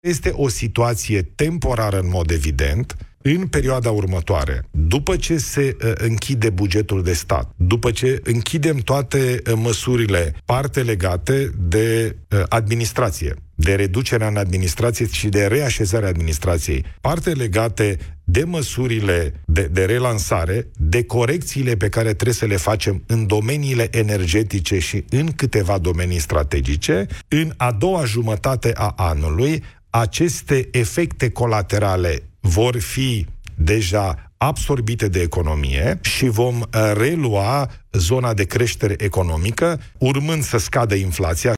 Recesiunea tehnică este o situație temporară, spune premierul Ilie Bolojan. Invitat în emisiunea România în direct de la Europa FM, prim-ministrul a spus că în a doua parte a anului vom asista la creștere economică și scăderea inflației.